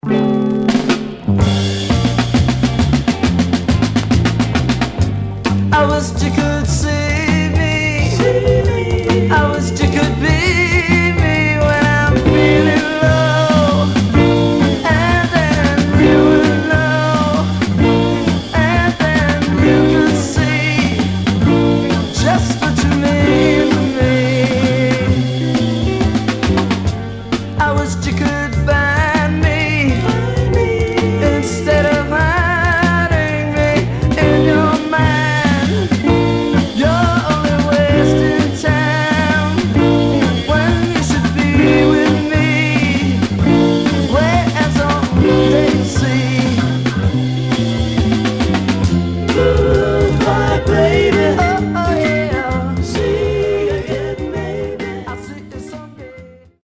Blue Eyed Soul Condition: M-
Stereo/mono Mono